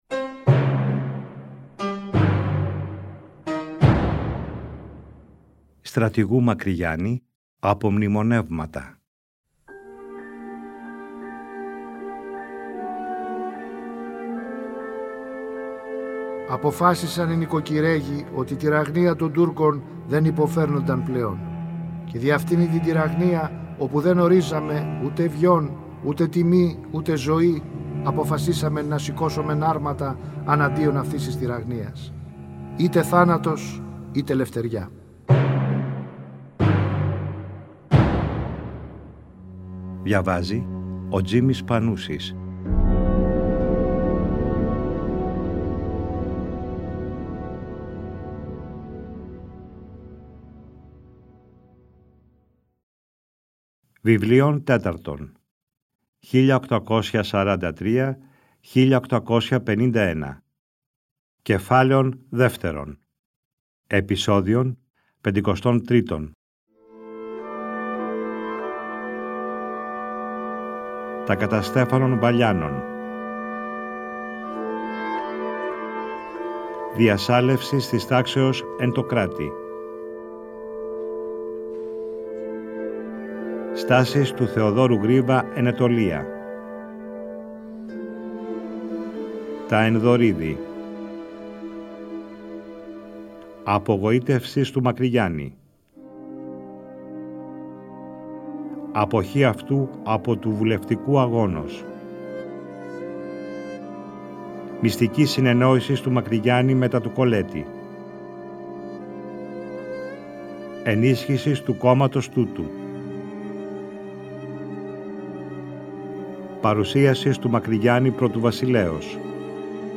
Τον Ιούνιο του 2012 το Τρίτο Πρόγραμμα παρουσίασε για πρώτη φορά μια σειρά 60 ημίωρων επεισοδίων, με τον Τζίμη Πανούση να διαβάζει τον γραπτό λόγο του Μακρυγιάννη, όπως ο ίδιος ο Στρατηγός τον αποτύπωσε στα “Απομνημονεύματα” του. Το ERT εcho σε συνεργασία με το Τρίτο Πρόγραμμα αποκατέστησαν ψηφιακά τα αρχεία.